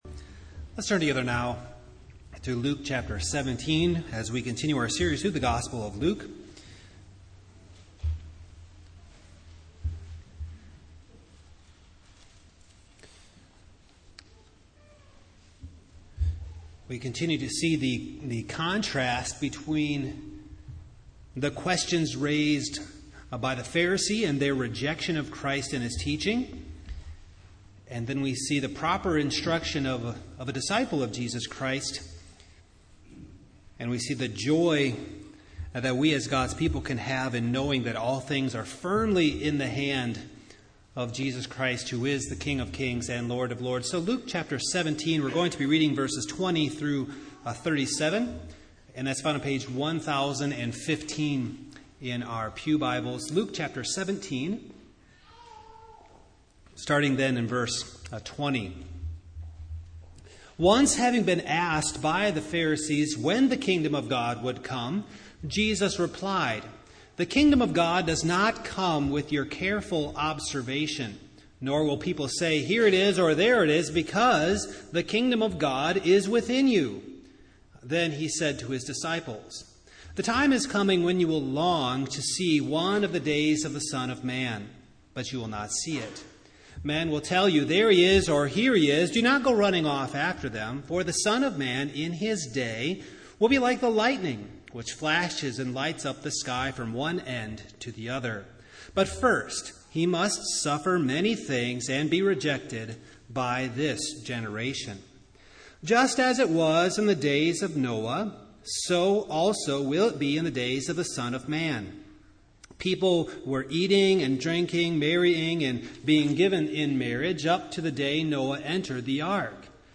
Passage: Luke 17:20-37 Service Type: Evening